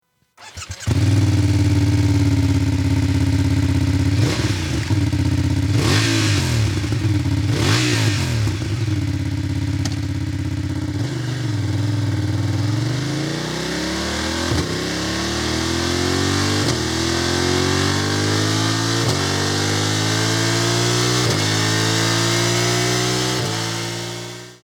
Sporty, bassy, sonorous noise - complete your BMW ✔ Premium components ✔ Integrated design ➜ Order now